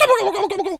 Animal_Impersonations
turkey_ostrich_hurt_gobble_02.wav